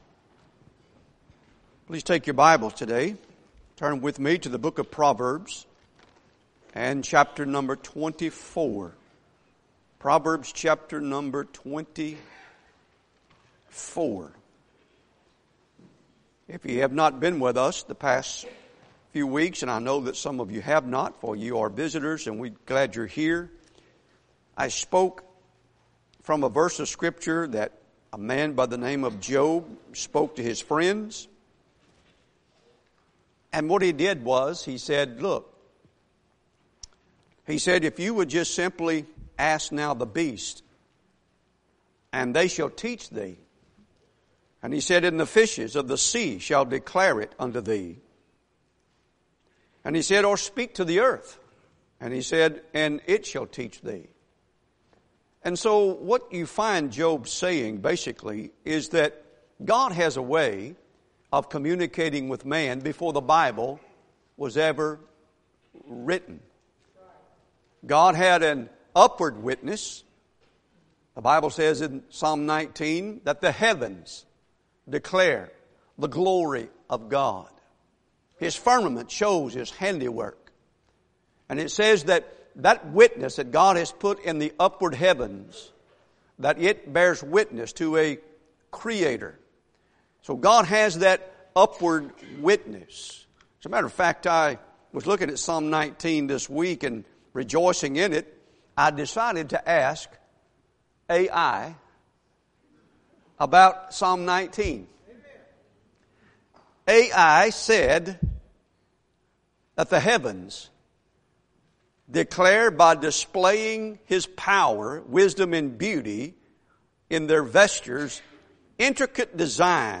Topic Sermons